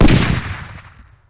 핸드캐논 사운드 추가